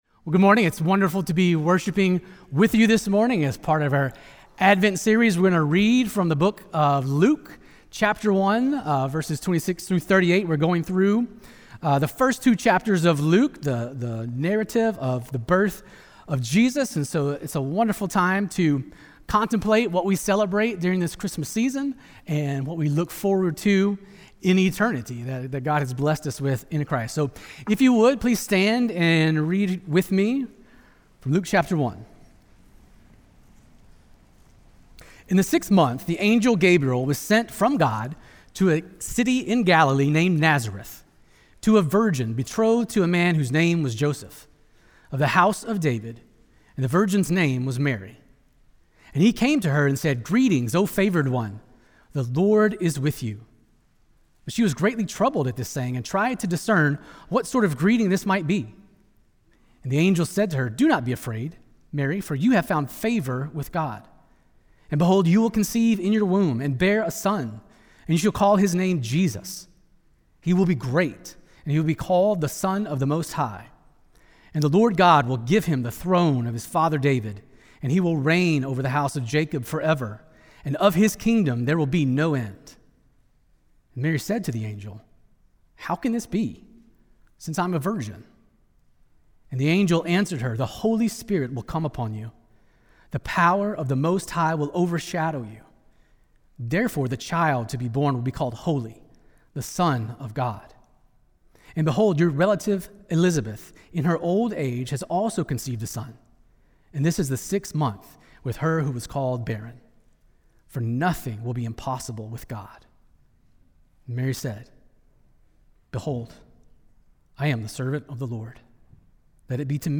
One of the ways that we pursue this mission is by gathering each Sunday for corporate worship, prayer, and biblical teaching.
Sermons